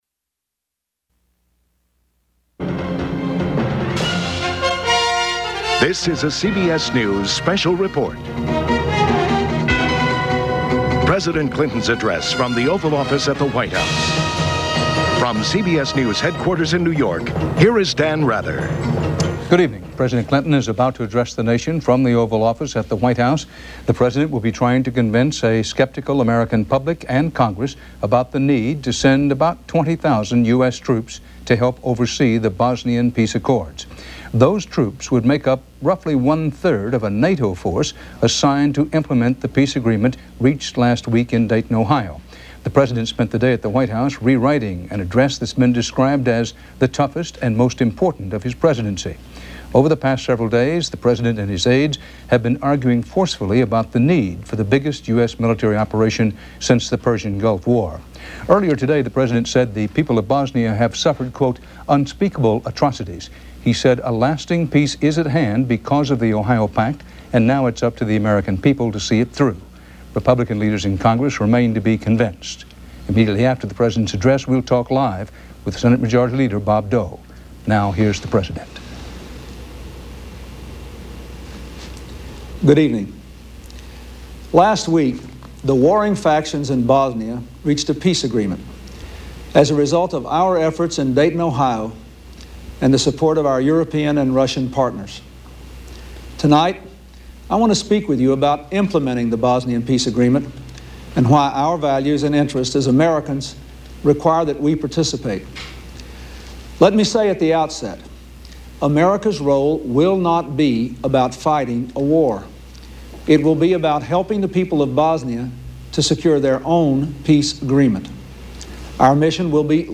U.S. President Bill Clinton's Oval Office address appealing for public support for sending U.S. troops to Bosnia